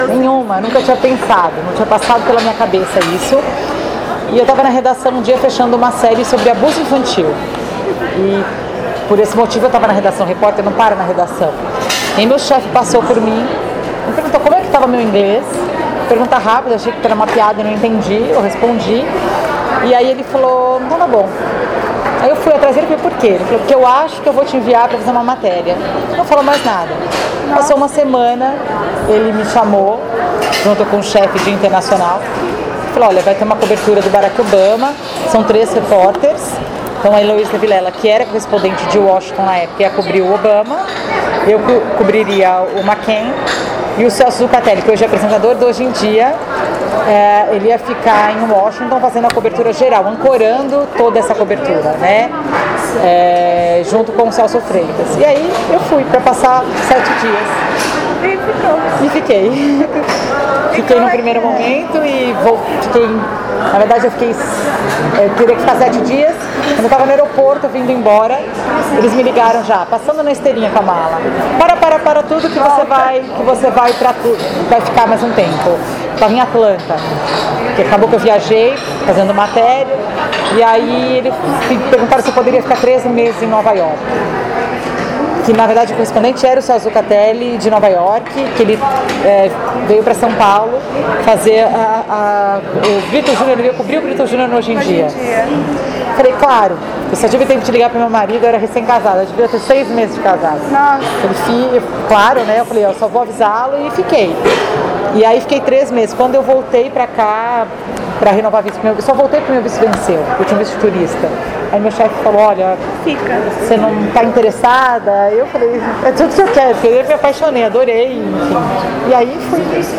Marcamos a entrevista em um shopping de São Paulo e, munidas de câmeras e gravadores, iniciamos a entrevista.
“Nunca tinha passado pela minha cabeça”, ela diz e conta o episódio de forma informal.